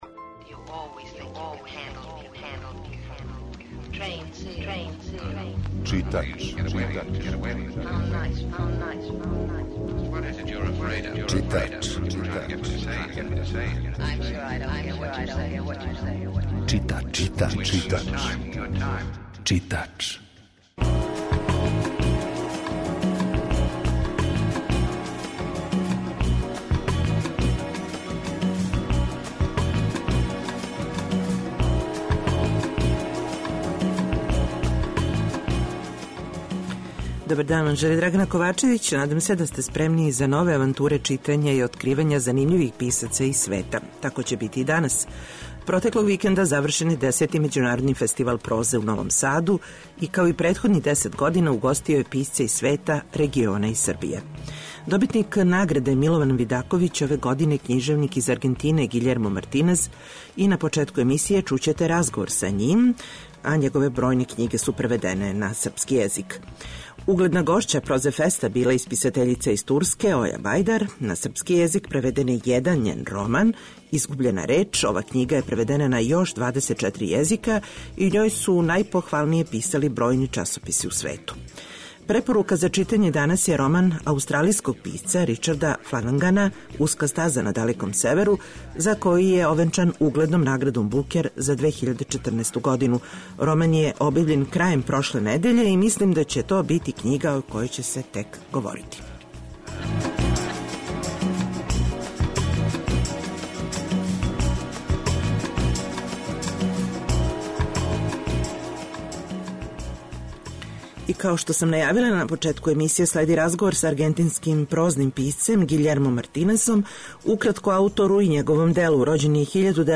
10. Међународни фестивал прозе у Новом Саду завршен је протеклог викенда, али ми смо забележили разговоре са некима од гостију из света.